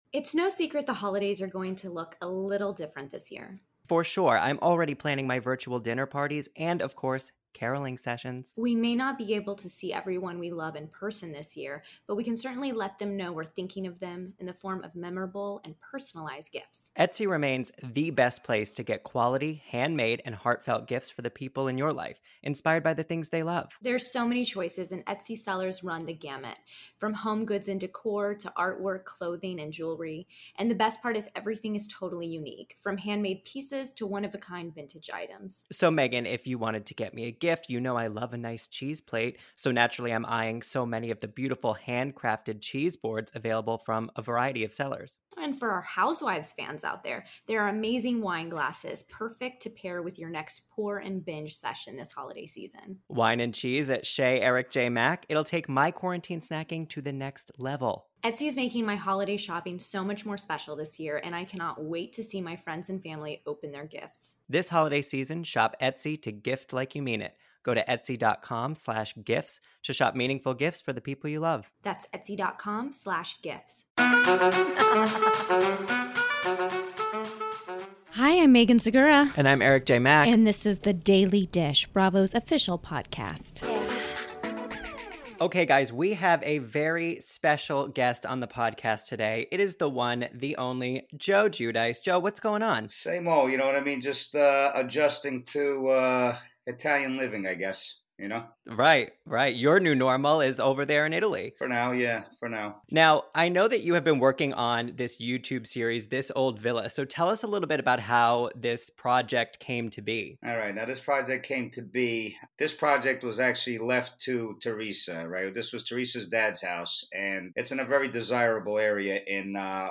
Joe calls in to share how he’s currently living in Italy, including his work renovating a family home in Sala Consilina — as seen on his YouTube series This Old Villa — working with luxury sex toy company Zalo USA, and his upcoming Celebrity Boxing match against Ojani Noa (Jennifer Lopez’s first husband) in the Bahamas.